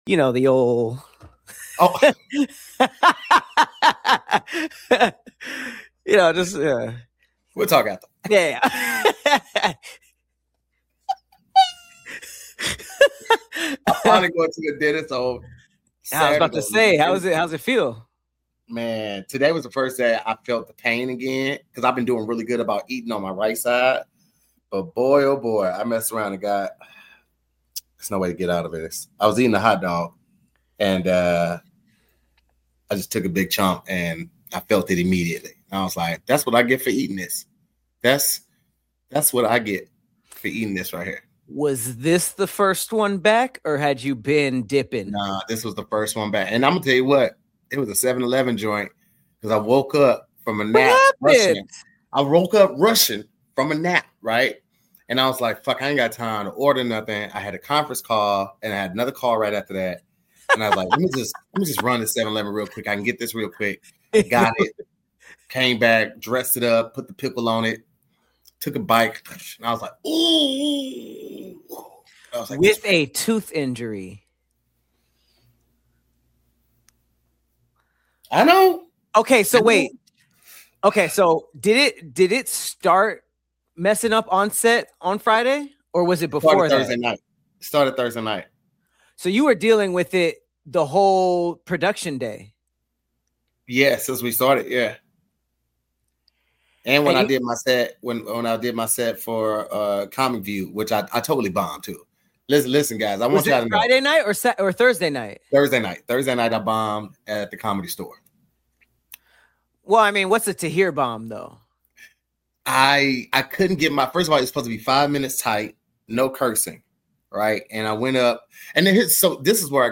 Just a fun conversation with a lot of laughs.